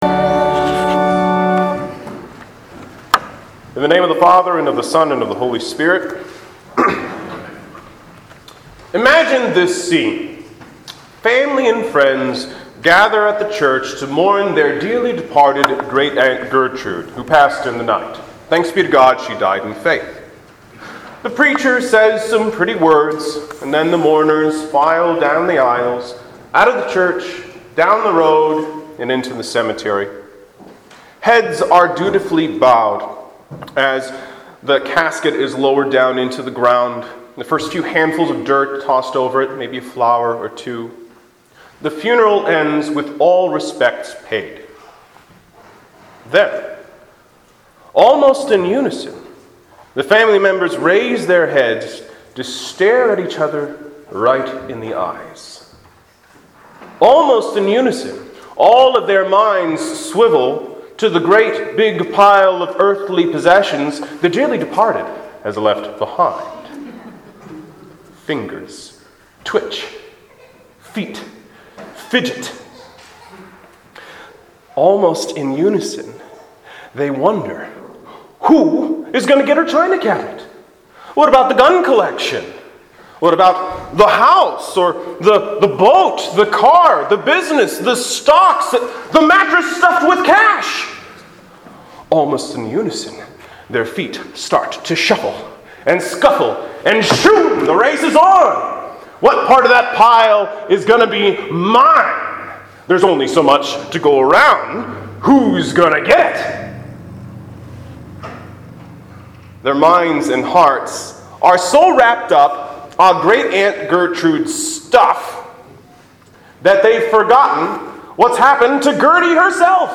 Sermons | Zion Lutheran Church LCMS